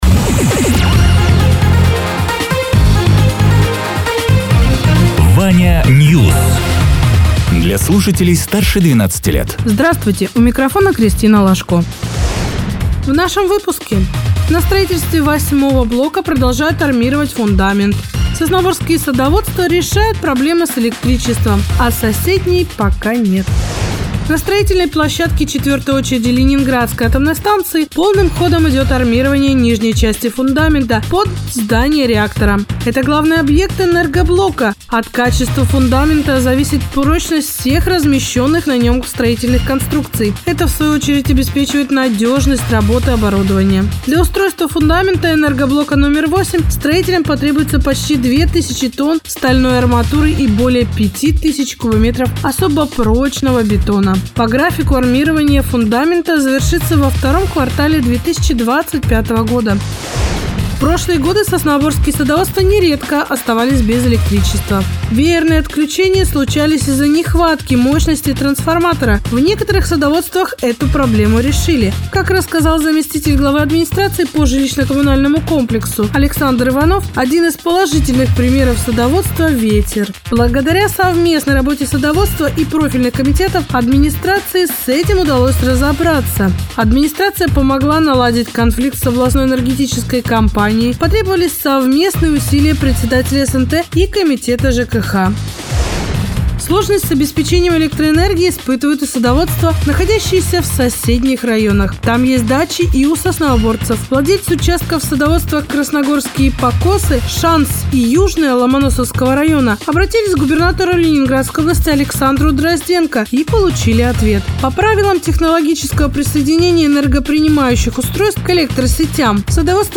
Радио ТЕРА 25.11.2024_08.00_Новости_Соснового_Бора